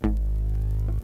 Acoustic Bass (All Falls Down).wav